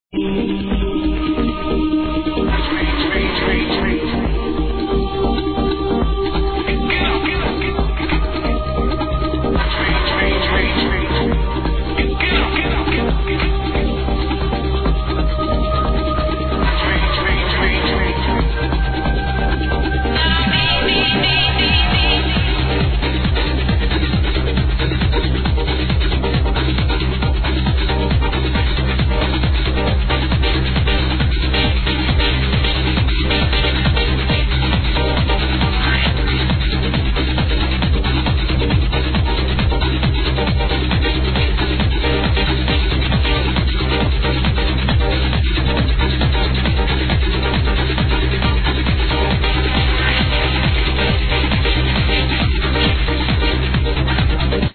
TranceAddict Forums > Tracks Section > Unknown Tracks > New Sample - 2001 Track with Male Vocal
Confused New Sample - 2001 Track with Male Vocal